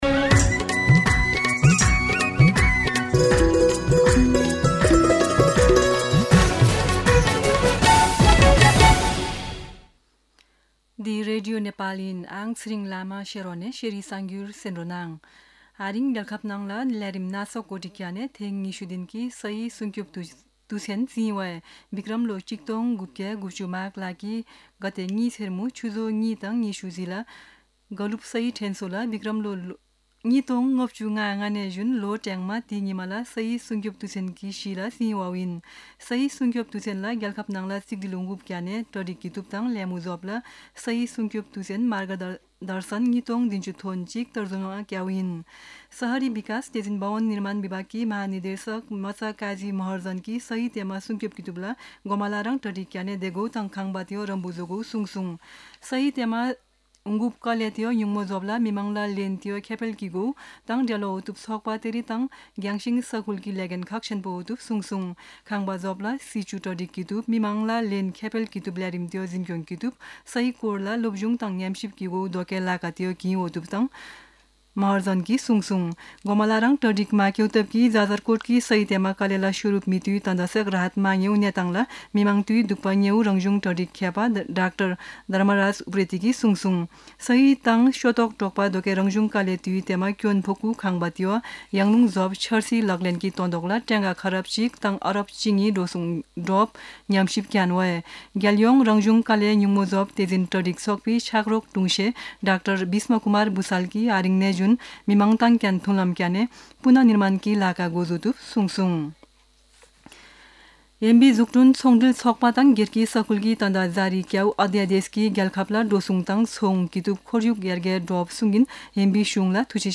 शेर्पा भाषाको समाचार : ३ माघ , २०८१
Sherpa-News-10-2.mp3